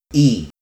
Vowels
ILike the y in city